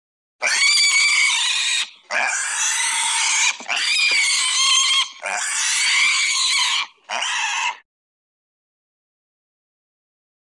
animal
Pig Screaming and Squealing